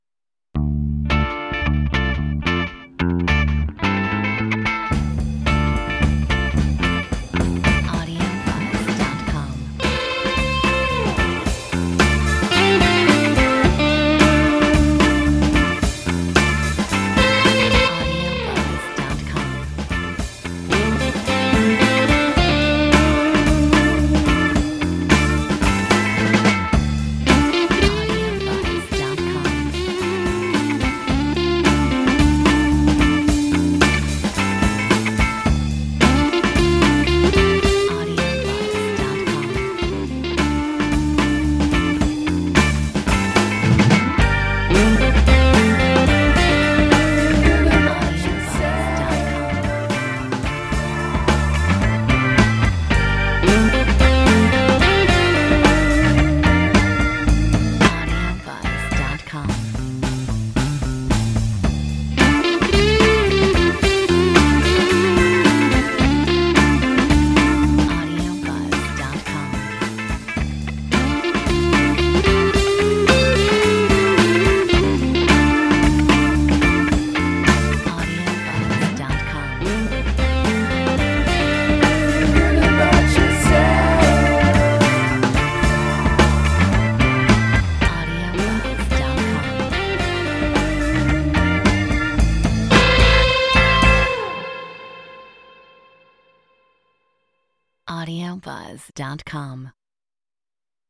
Metronome 110 BPM
Classic Rock Retro-Rock Rock